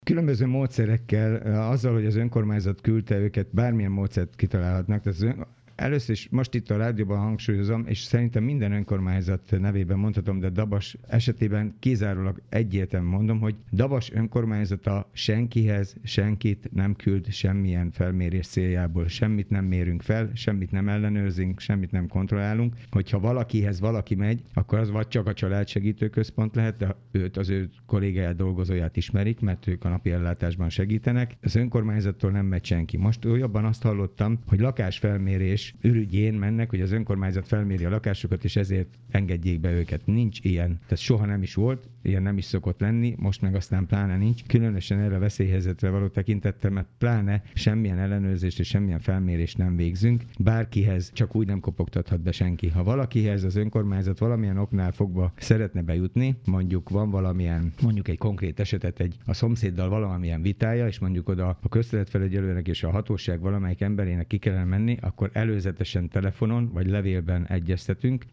Kőszegi Zoltán polgármester erősítette meg, hogy nincs szó személyes látogatásról semmilyen esetben.